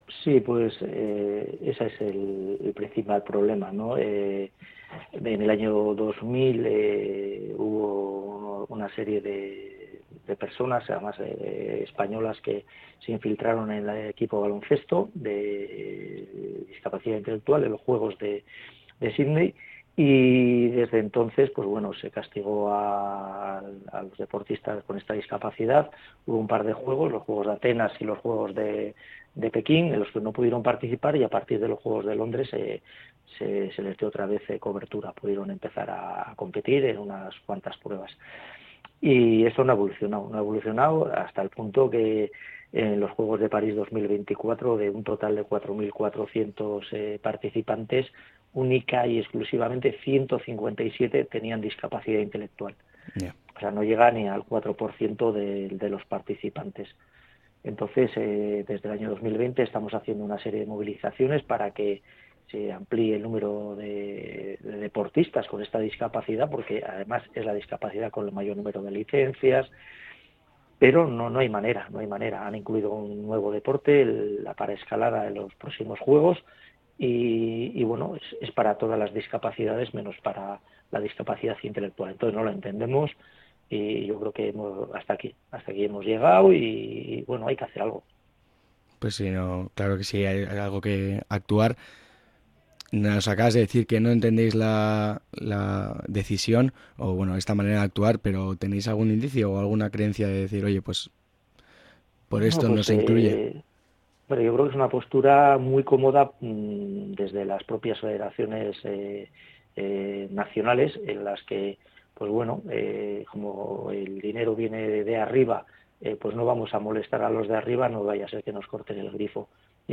JAVI-CONDE-ENTREVISTA.mp3